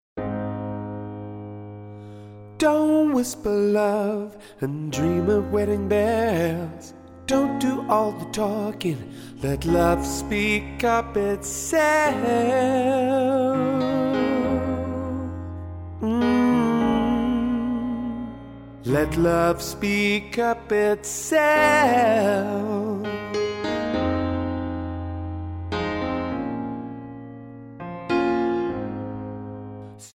--> MP3 Demo abspielen...
Tonart:Ab Multifile (kein Sofortdownload.
Die besten Playbacks Instrumentals und Karaoke Versionen .